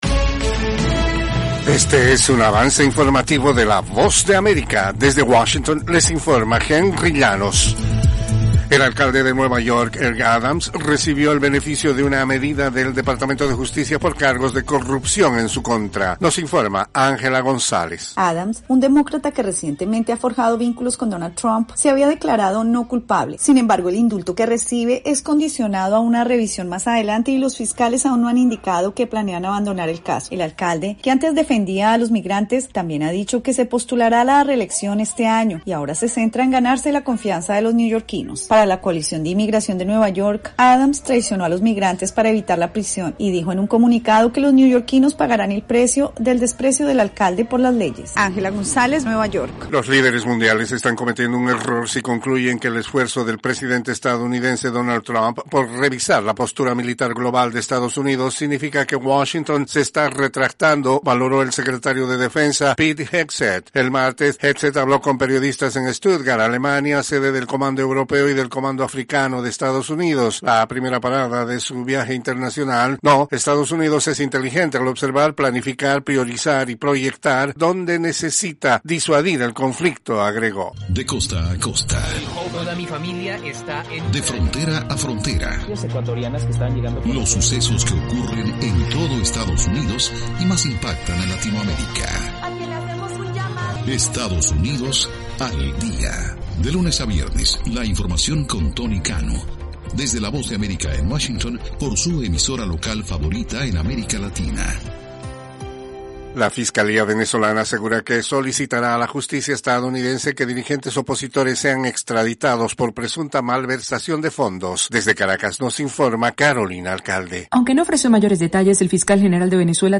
Cápsula informativa de tres minutos con el acontecer noticioso de Estados Unidos y el mundo.